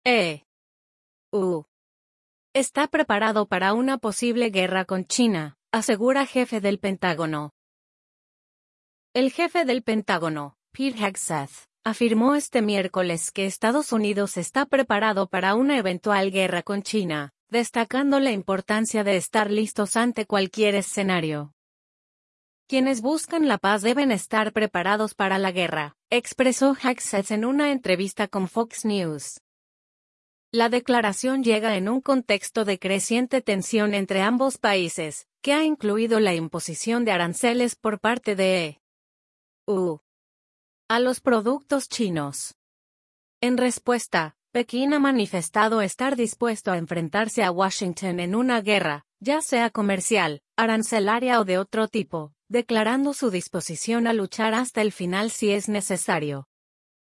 “Quienes buscan la paz deben estar preparados para la guerra”, expresó Hegseth en una entrevista con Fox News.